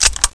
galil_boltpull.wav